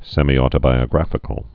(sĕmētə-bīə-grăfĭ-kəl, sĕmī-)